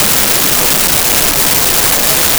Family At Indoor Restaurant
Family at Indoor Restaurant.wav